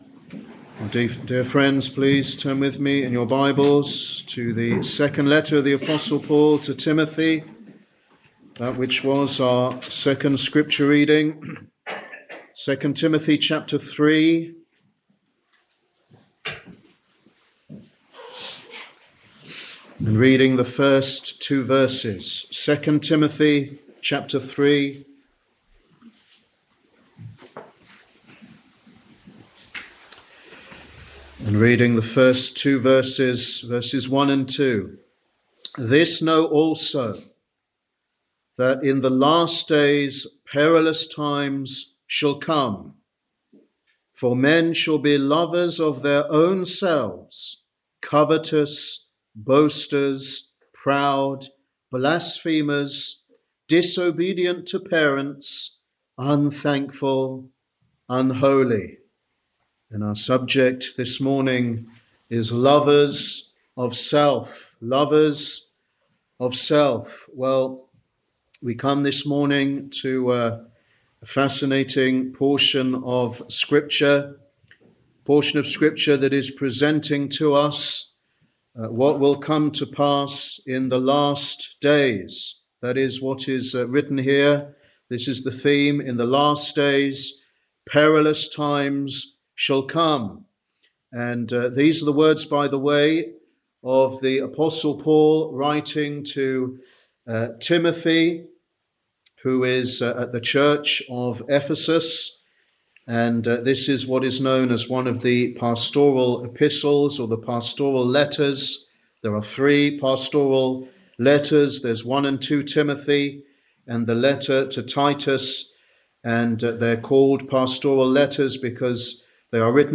An independent reformed baptist church, founded in 1877